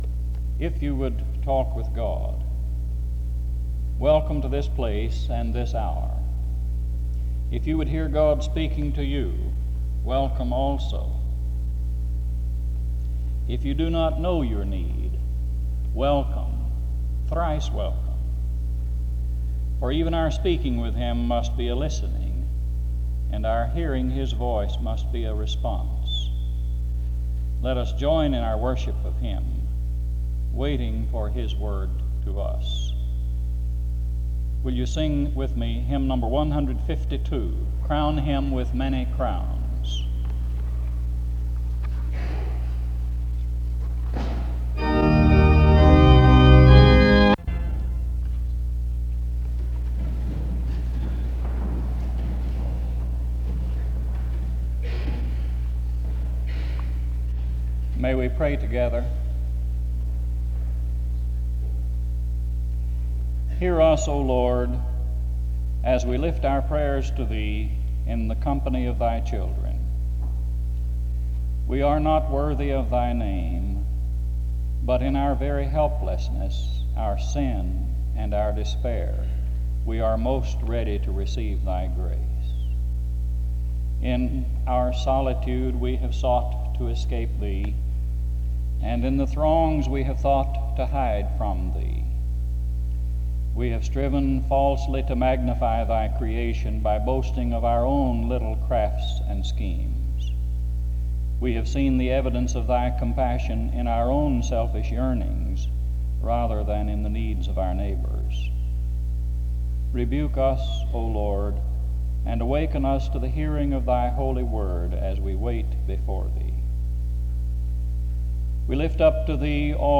This service begins with a welcome to those in attendance from 0:00-0:33. A prayer is offered from 1:03-3:08. John 13:1-20 is read from 3:12-5:57.
Music plays from 7:03-8:56.
He preaches on how followers of Jesus are to be humble servants. Closing music plays from 27:00-27:36.